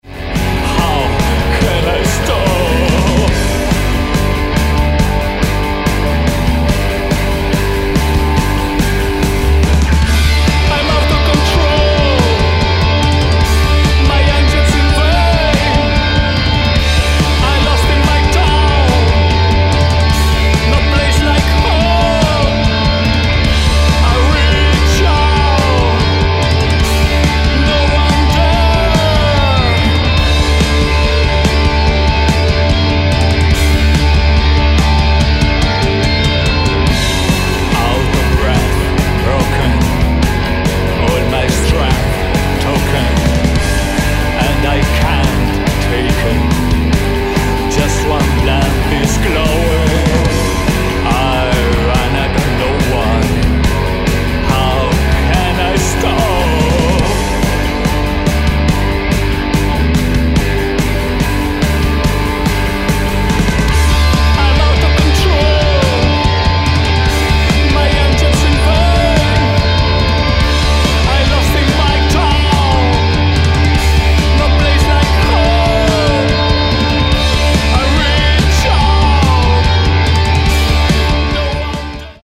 Batcave / Deathrock